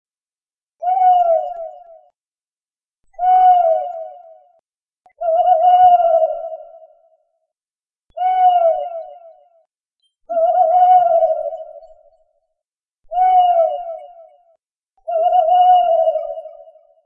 Waldkauz Ruf
• Er ruft nachts mit einem tiefen, klagenden „Huu-huu“, das oft in Filmen verwendet wird.
Waldkauz-Ruf-isoliert-Voegel-in-Europa.mp3